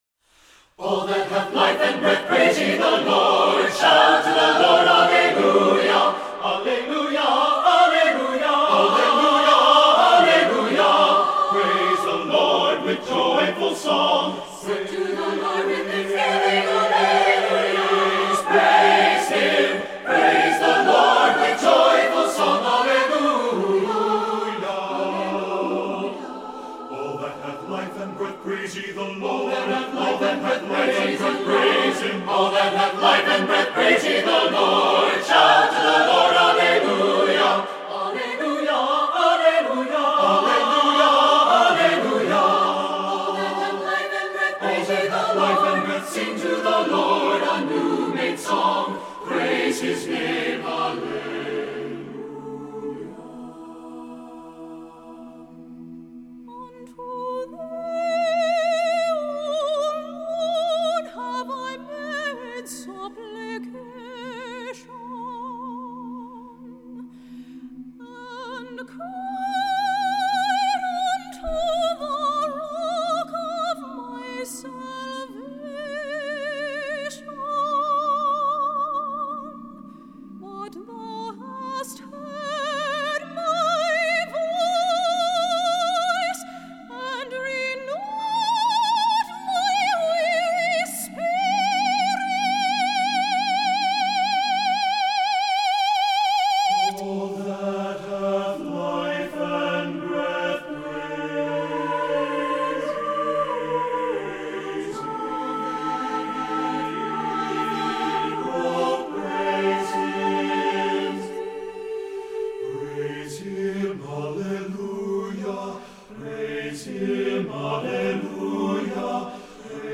Voicing: SSATTB